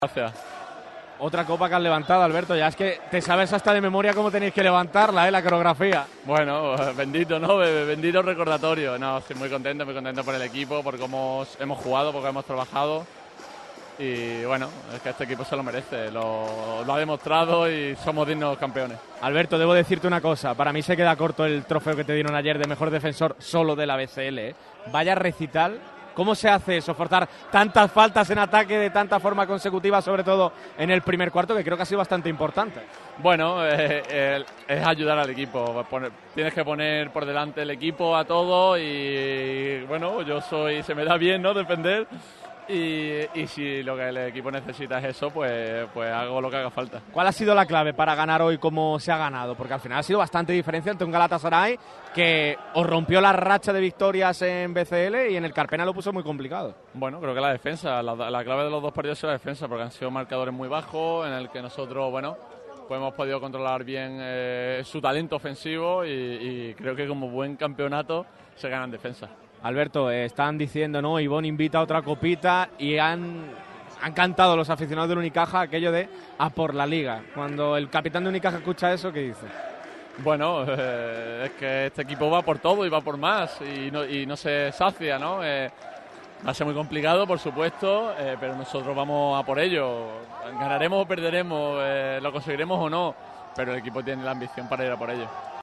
Las reacciones de los campeones de la BCL sobre el parqué del Sunel Arena.
ALBERTO DÍAZ, JUGADOR